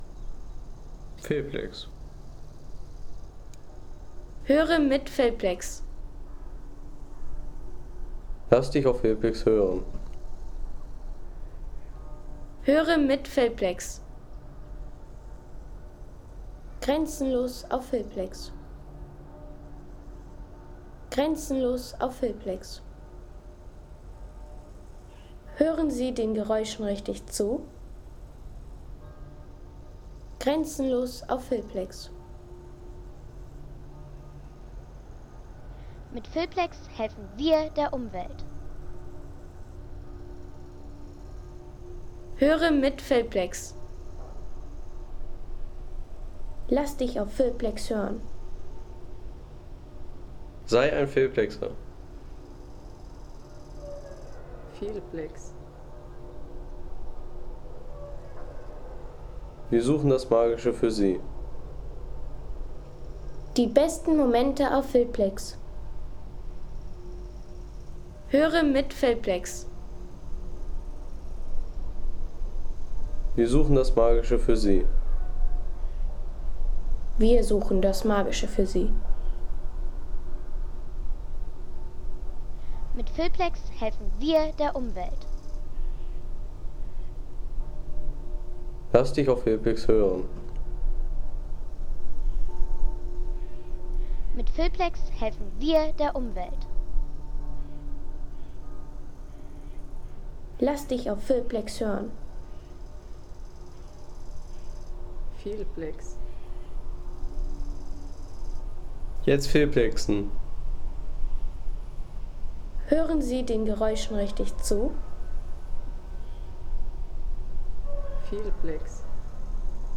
Naturstimmung aus Mayrwinkl
Klang des goldenen Herbstes – Naturstimmung aus Mayrwinkl.